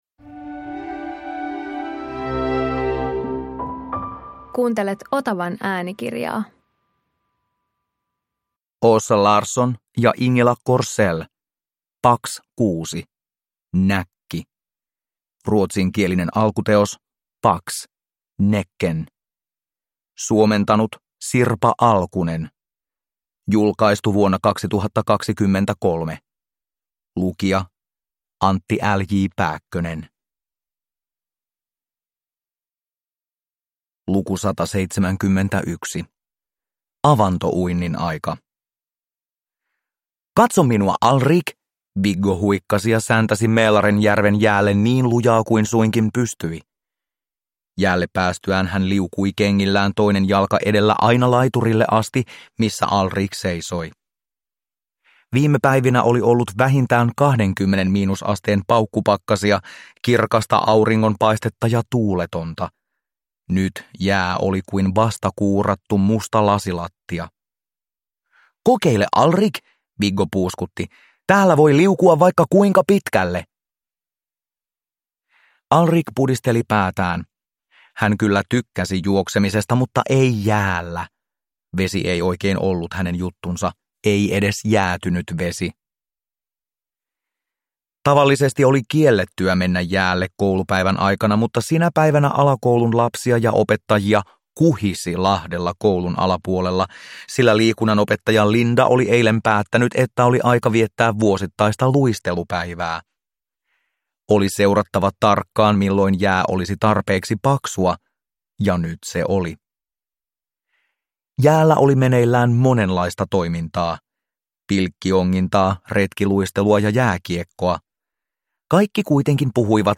Pax 6 - Näkki – Ljudbok – Laddas ner